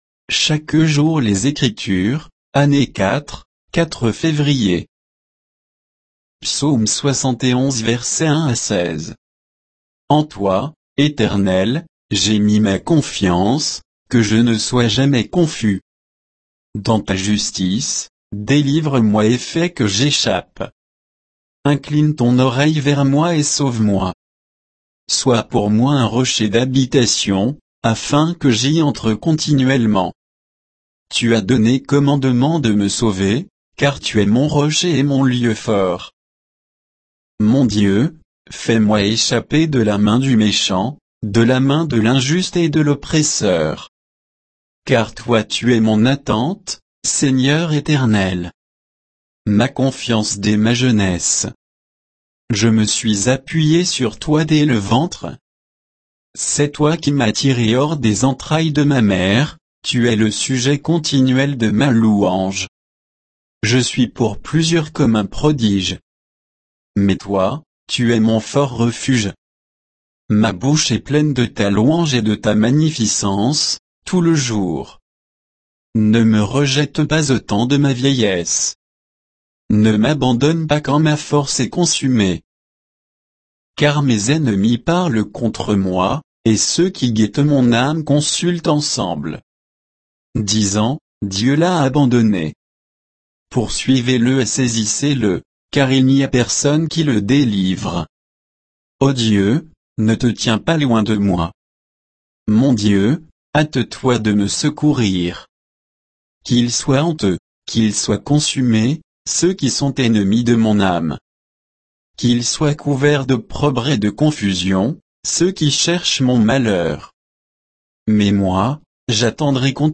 Méditation quoditienne de Chaque jour les Écritures sur Psaume 71